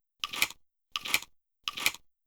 Shotgun Shell Load.wav